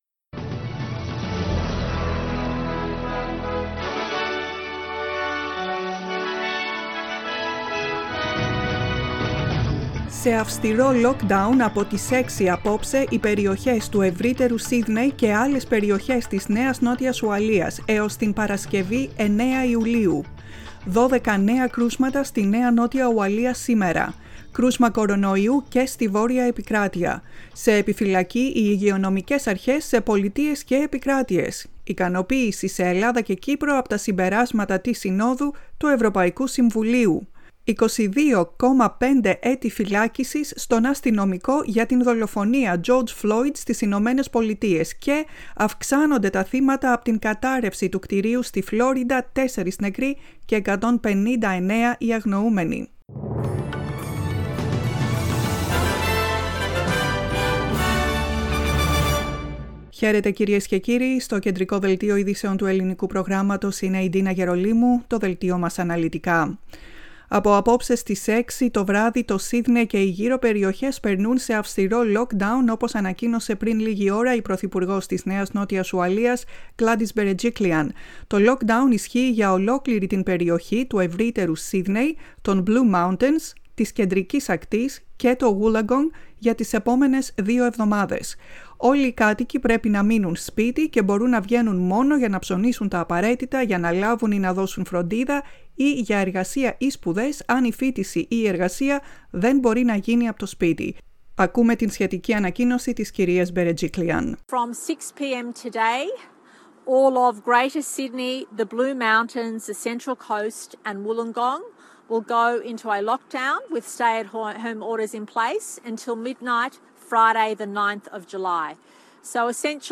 Listen to the main bulletin of the Greek Language Program.